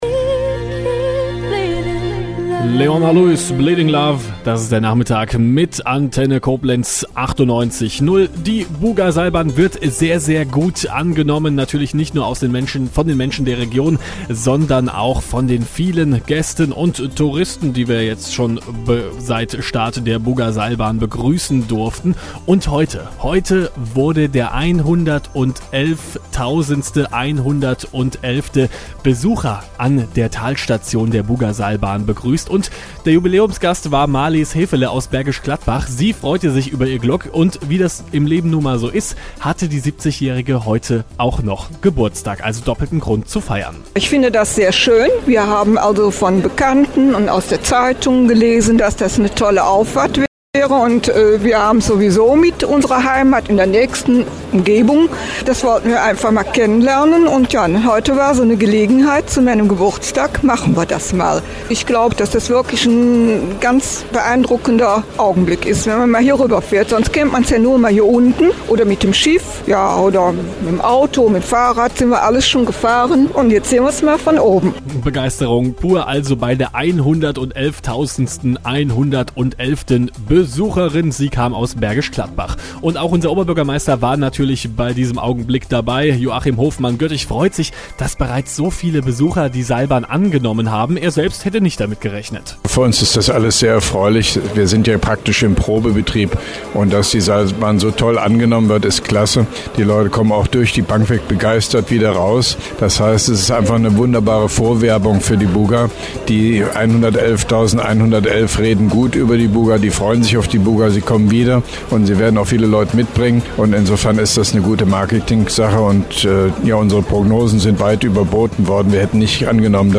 (2) Koblenzer OB Radio-Bürgersprechstunde 07.09.2010